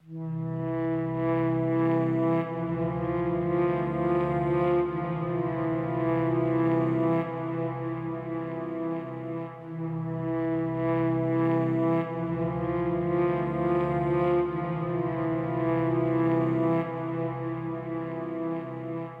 凯尔特人颤音长笛的旋律
描述：漂亮的混响长笛旋律，带有颤音，凯尔特人风格，A小调。
标签： 100 bpm Ethnic Loops Woodwind Loops 3.23 MB wav Key : Unknown
声道立体声